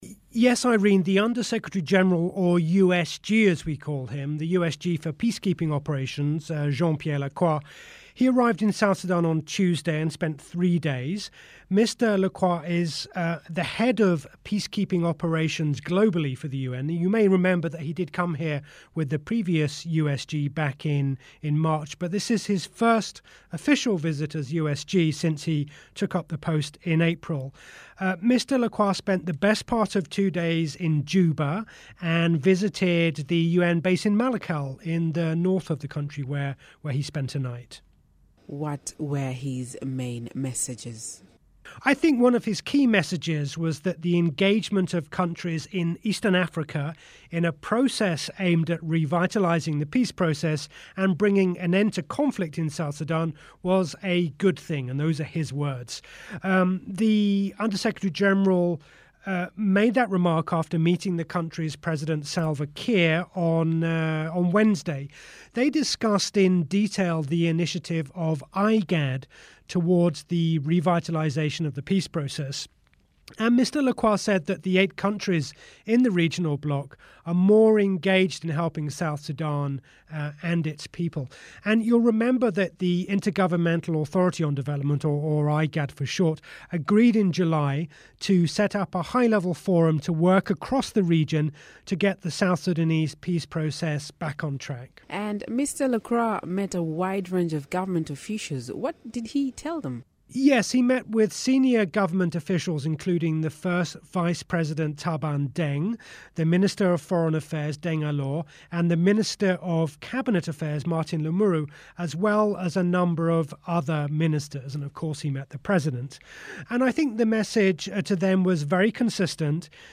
Miraya Breakfast Show